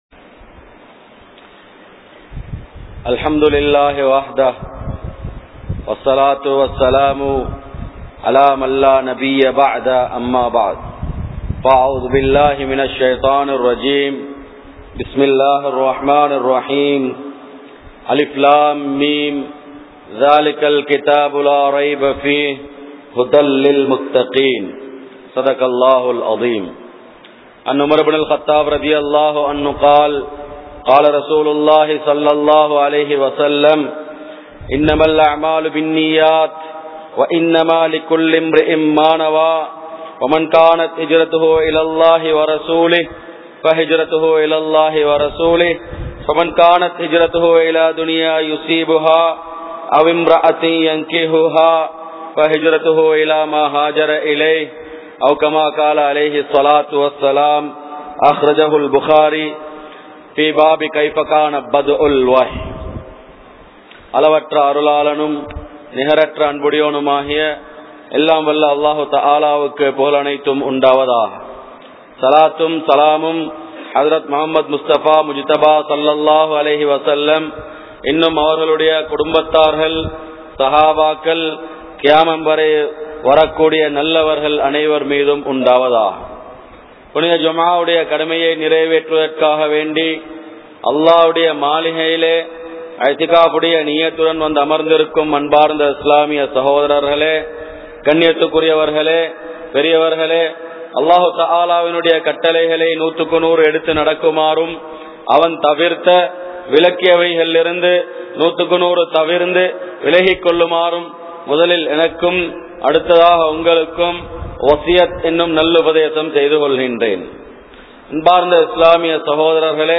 Fathuwa (பத்வா) | Audio Bayans | All Ceylon Muslim Youth Community | Addalaichenai